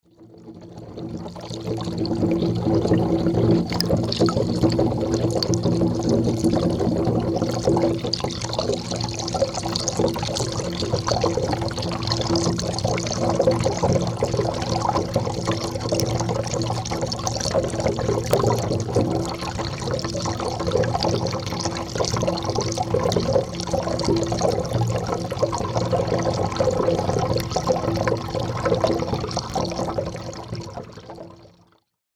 Gemafreie Sounds: Handwerker
mf_SE-6374-underwater_-_water_tank.mp3